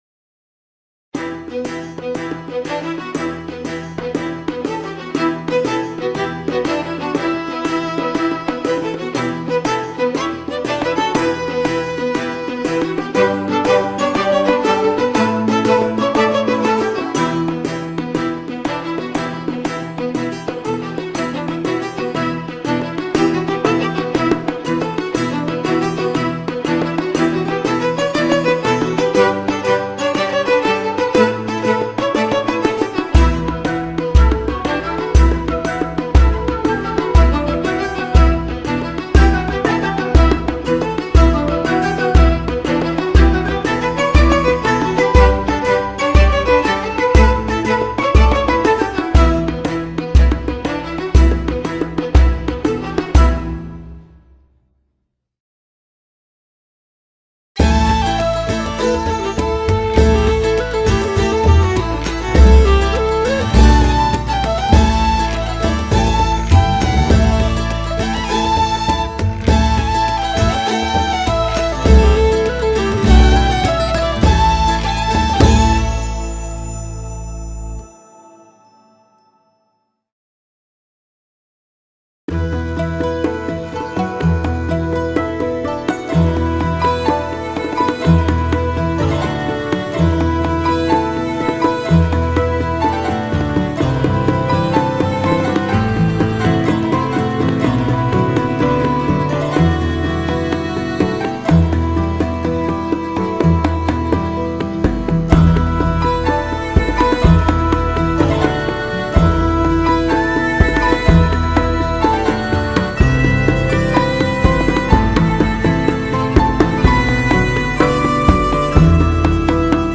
RPG Playlist - Tavern_Inn Music-fIuO3RpMvHg.opus